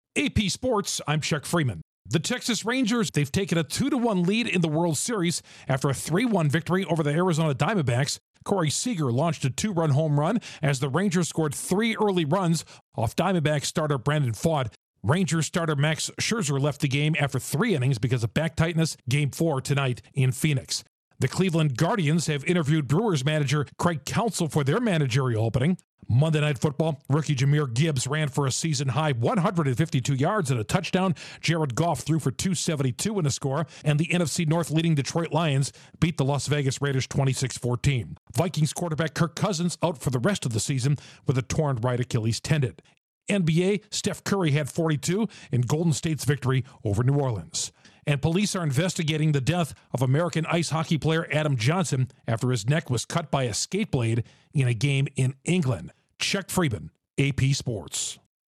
The Rangers have taken a 2-1 lead in the World Series, Craig Counsell interviews with the Guardians, Lions plow through the Raiders, and Vikings quarterback Kirk Cousins is out for season. Correspondent